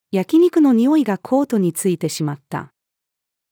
焼き肉の匂いがコートに付いてしまった。-female.mp3